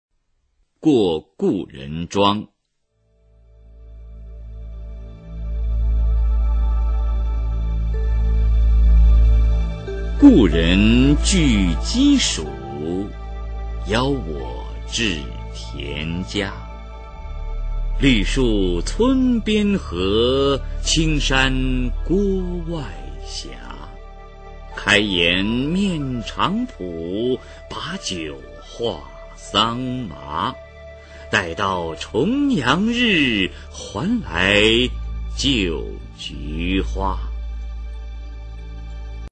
[隋唐诗词诵读]孟浩然-过故人庄 配乐诗朗诵